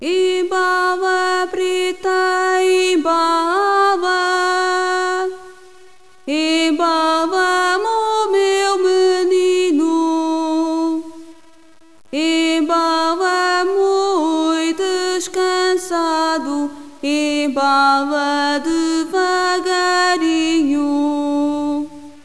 FOLKMUSIC
This is a portion of a lullaby, or cradle song.